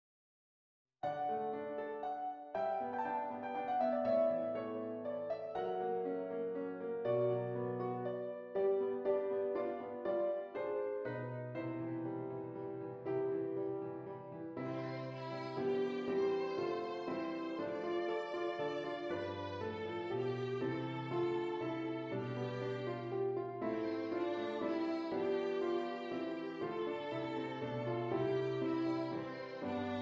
Violin Solo with Piano Accompaniment
Does Not Contain Lyrics
C Major
Moderately